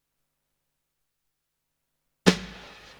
snare3.wav